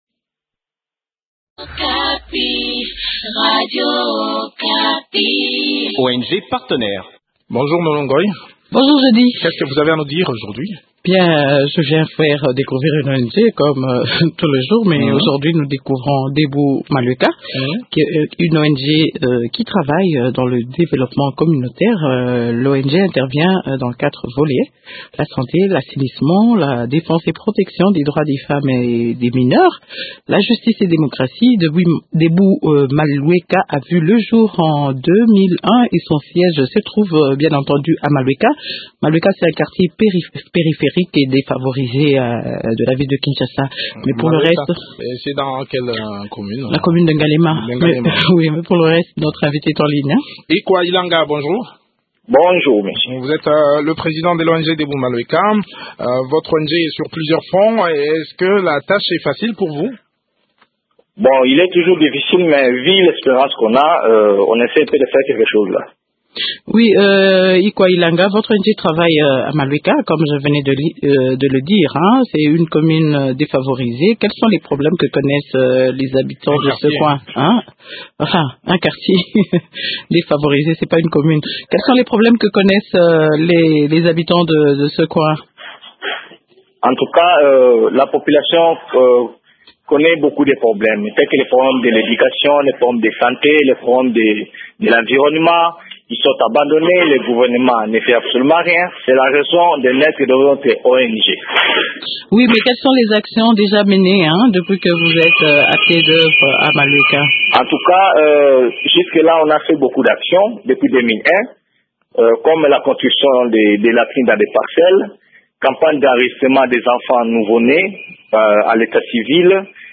parlent des activités de cette structure dans cet entretien